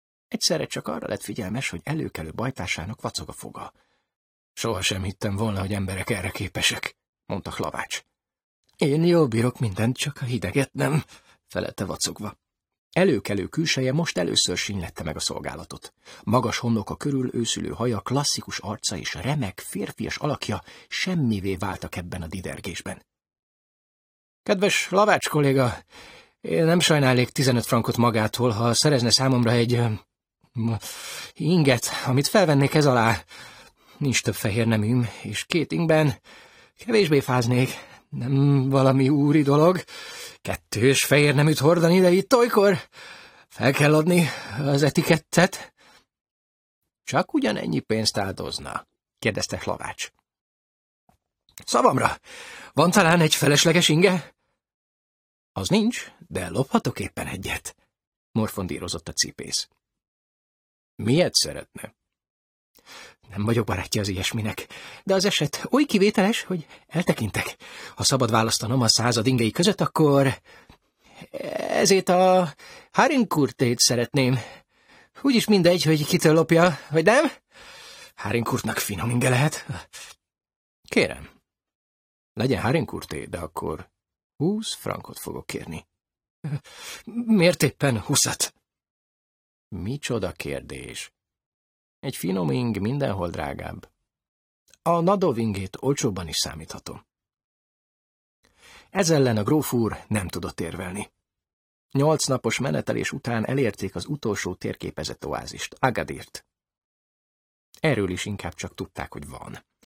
Az előretolt helyőrség (Online hangoskönyv